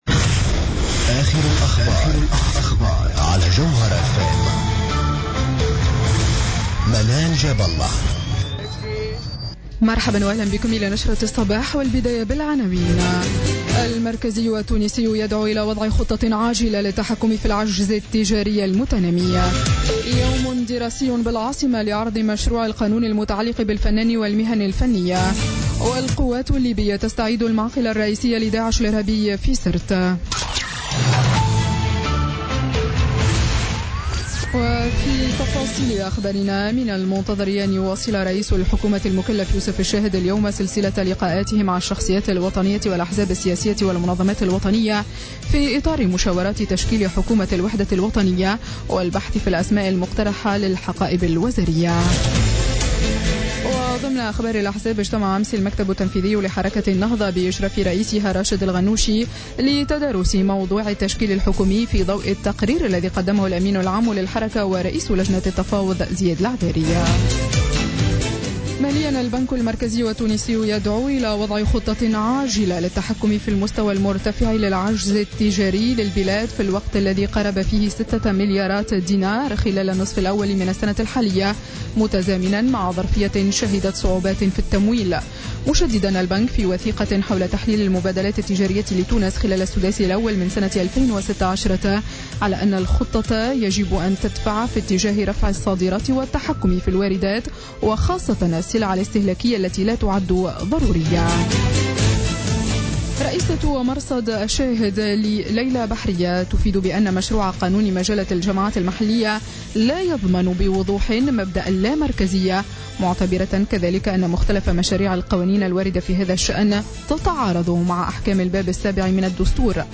نشرة أخبار السابعة صباحا ليوم الخميس 11 أوت 2016